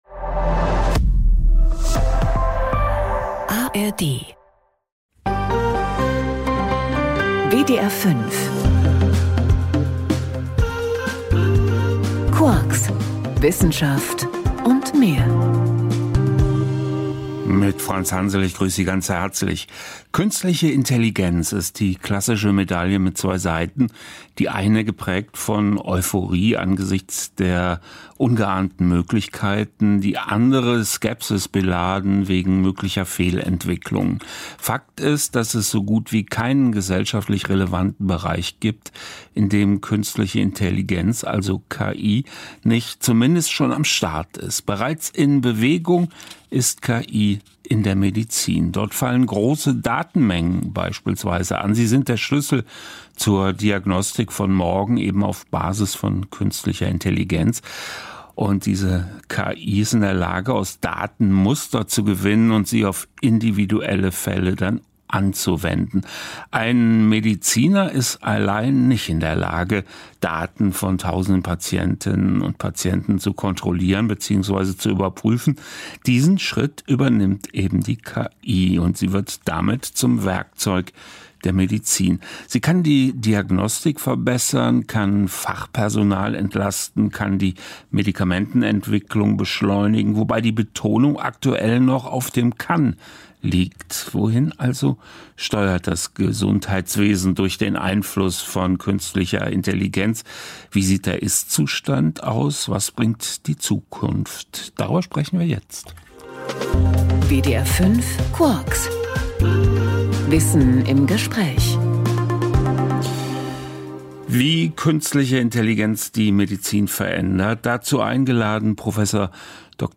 Interview zum PLRI SmartCar